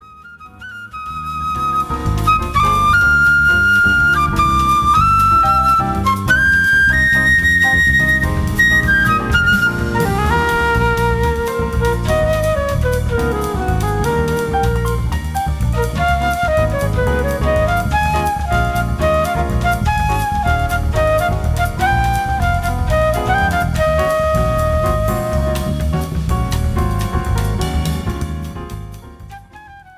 Sensuous female vocals